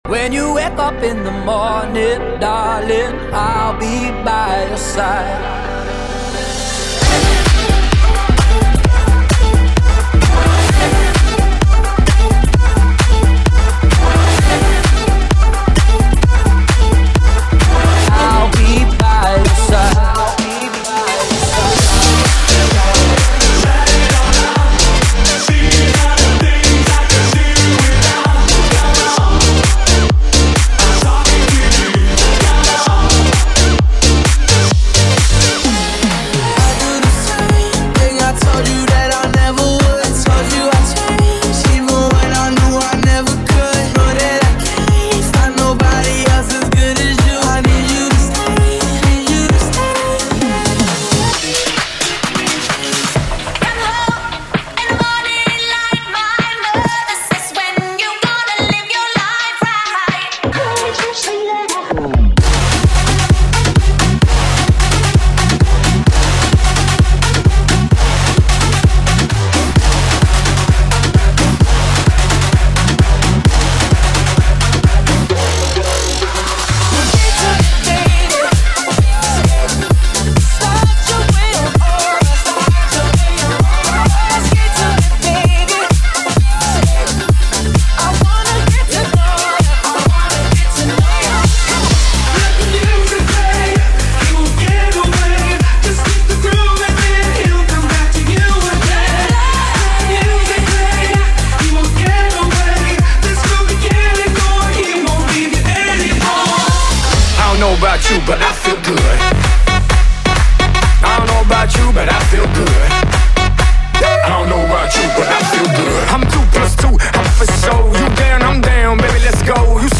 BPM: 130|140|150 (56:00)